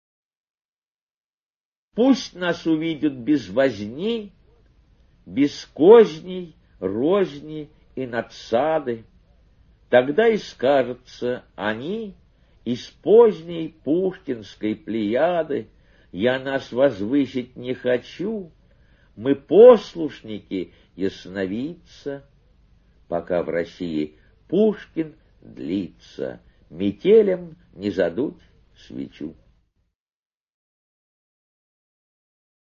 1. «Самойлов Д. (голос автора) – Пусть нас увидят без возни…» /